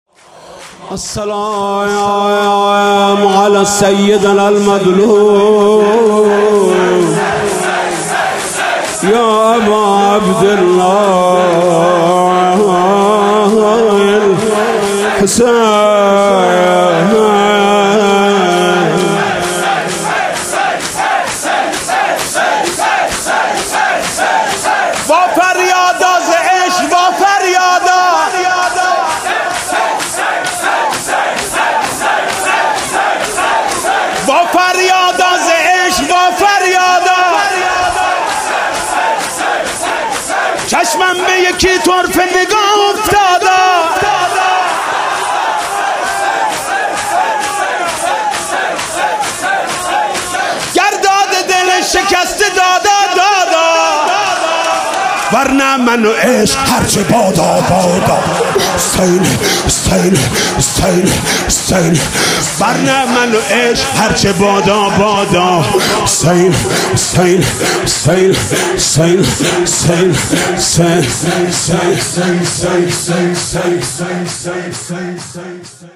صوت/ عزادارى شب اول فاطمیه با مداحى حاج محمود کریمى
شــور ( وافريادا ز عشــق وا فريادا )
فاطمیه حاج محمود کریمی مداحی